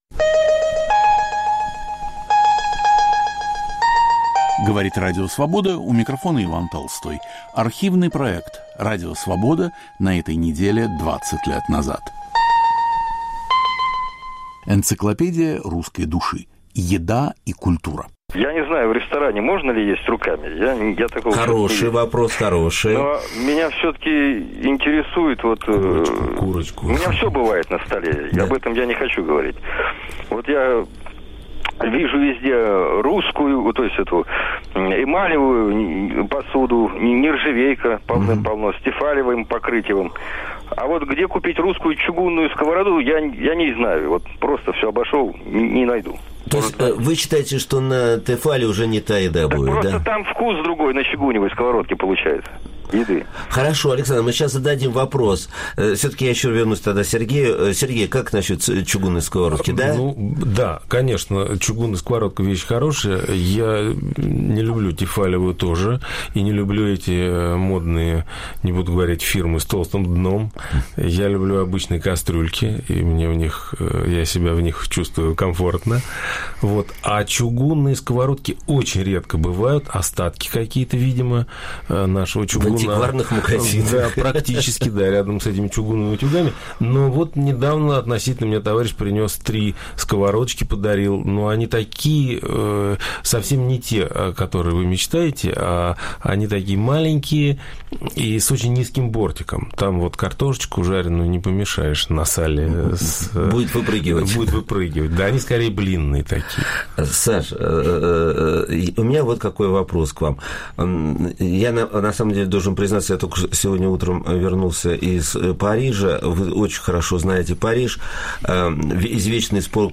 Передачу подготовил и ведет Виктор Ерофеев.